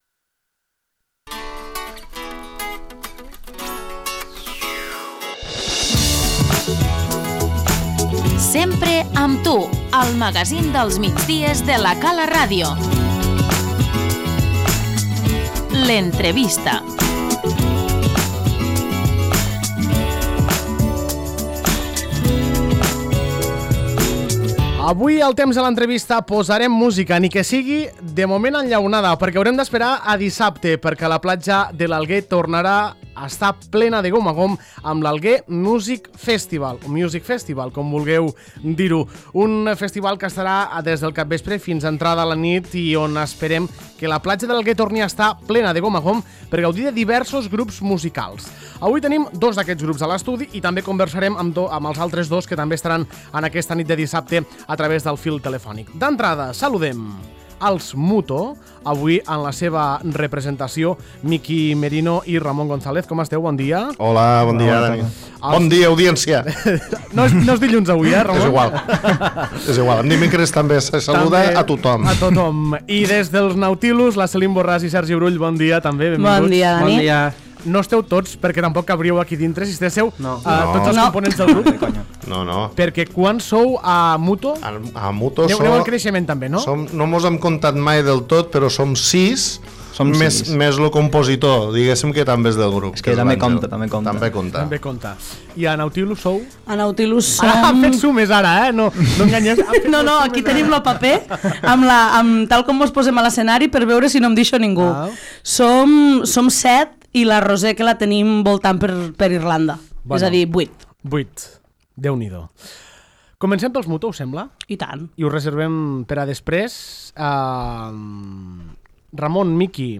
L'entrevista - Alguer Music Festival